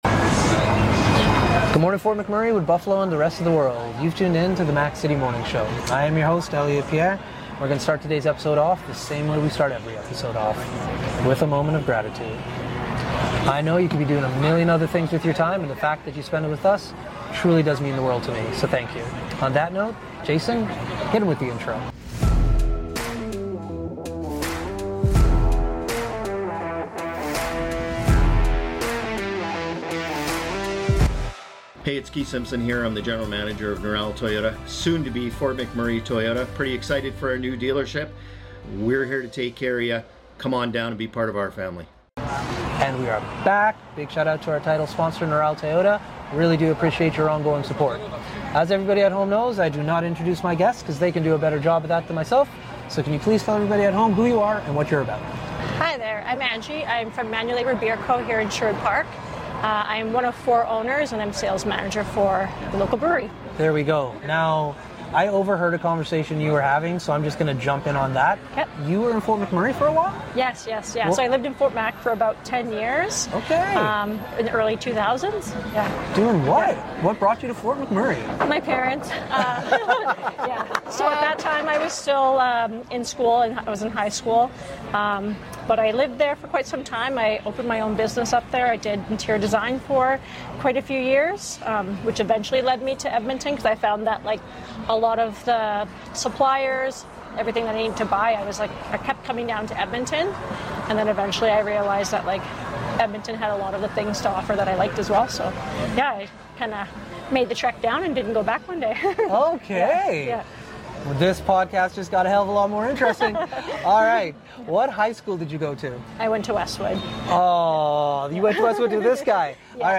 On location at Cask and Barrel in Edmonton